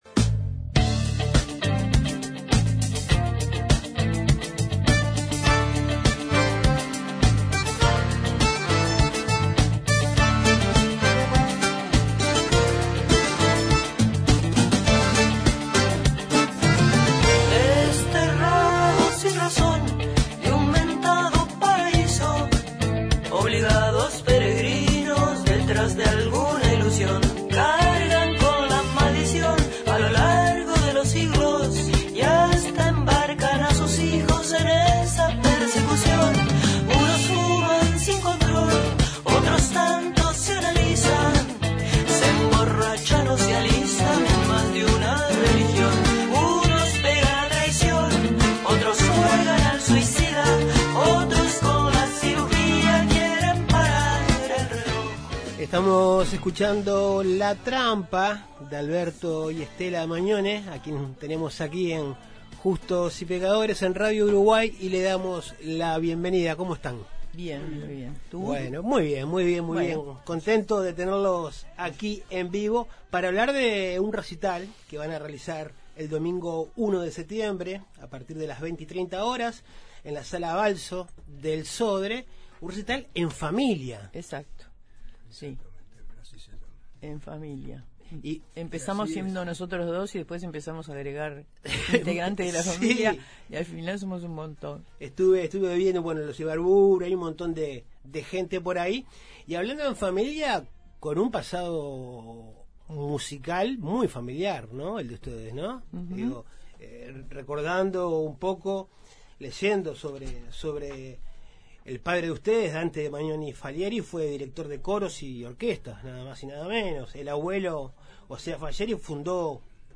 En Justos y pecadores recibimos a ambos músicos, quienes explicaron los pormenores del show.